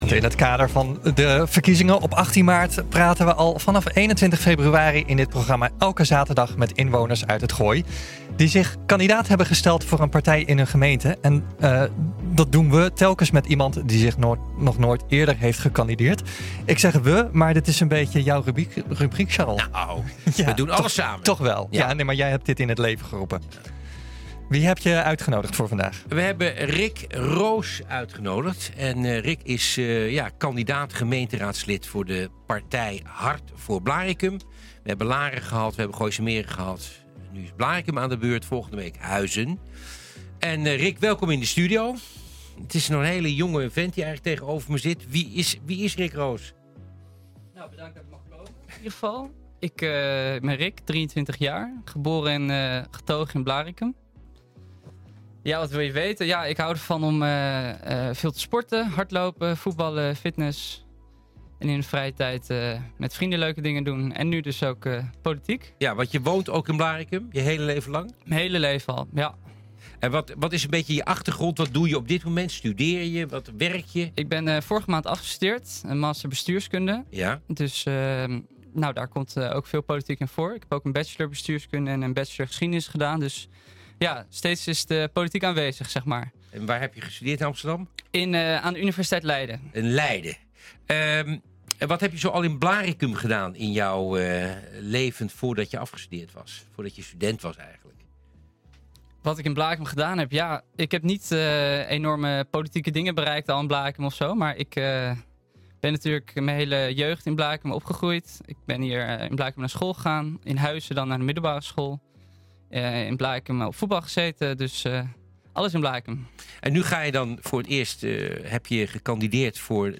In het kader van de komende gemeenteraadsverkiezingen op 18 maart praten we vanaf 21 februari in dit programma elke zaterdag met inwoners uit het Gooi, die zich kandidaat hebben gesteld voor een partij in hun gemeente.